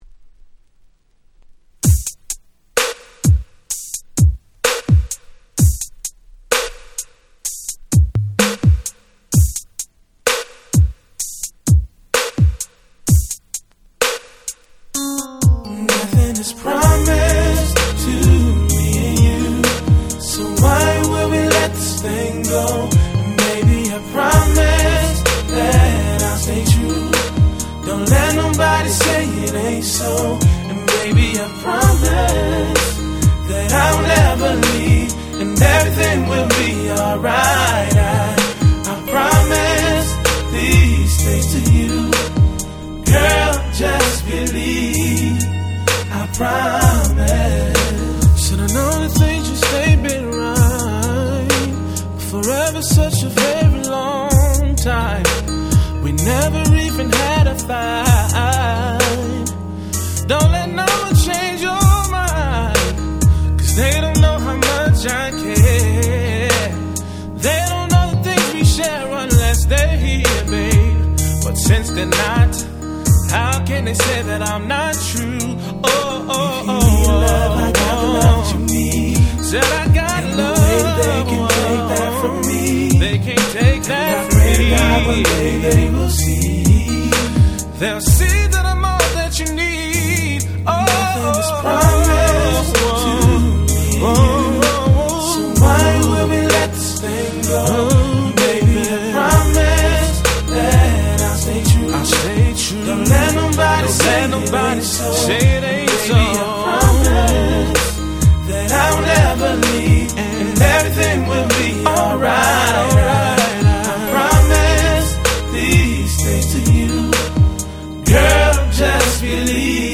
99' Super Hit R&B / Slow Jam !!